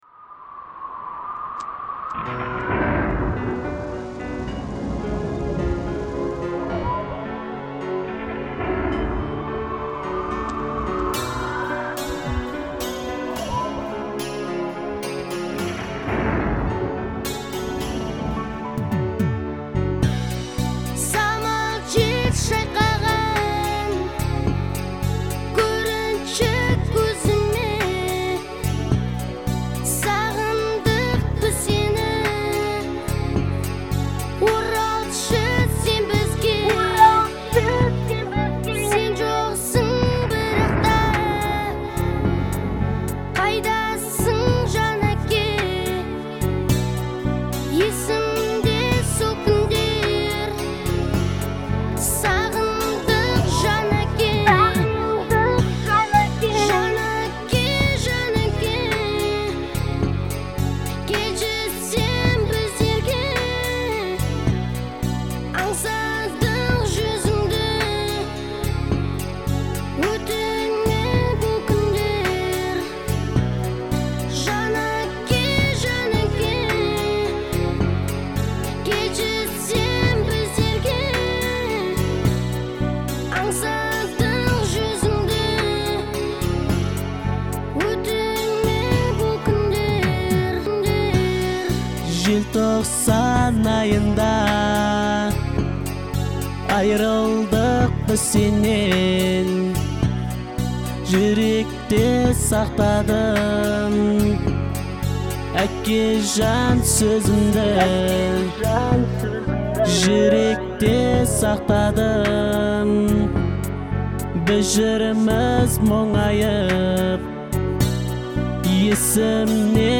Вокальная группа